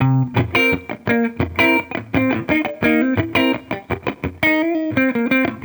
Index of /musicradar/sampled-funk-soul-samples/85bpm/Guitar
SSF_TeleGuitarProc1_85C.wav